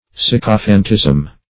Sycophantism \Syc"o*phant*ism\, n.